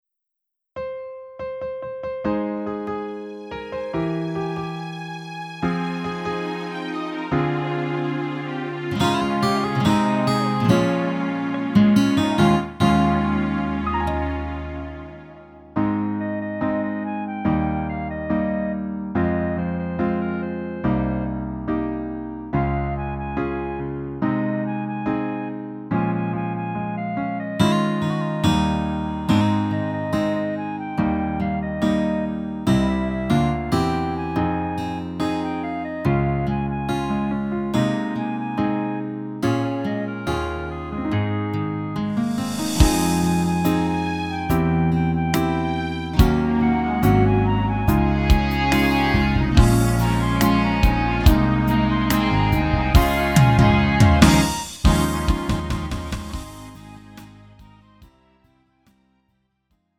음정 -1키 3:11
장르 가요 구분 Lite MR